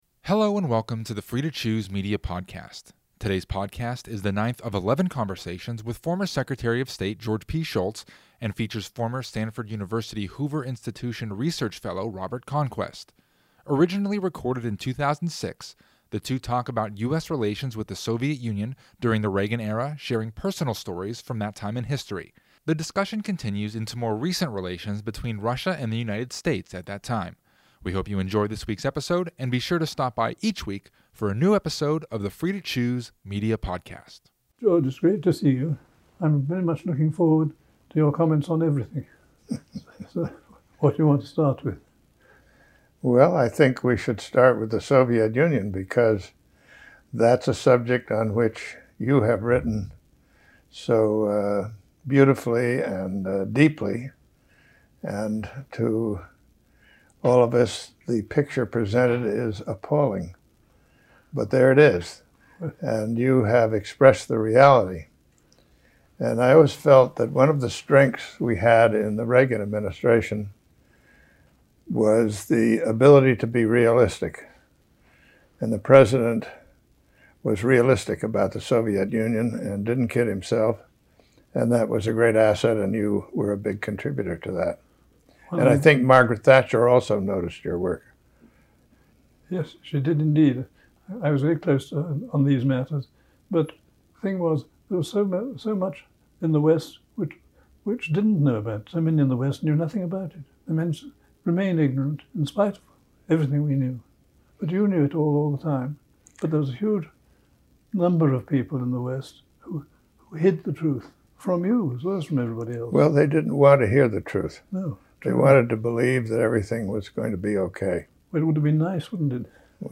This 2006 conversation is the ninth of eleven conversations with former Secretary of State George P. Shultz and features former Stanford University Hoover Institution research fellow Robert Conquest. This conversation includes an intimate look at U.S. relations with the Soviet Union during the Reagan era. Throughout the discussion, the two share stories from the past and delve into more recent relations between Russia and the United States at that time.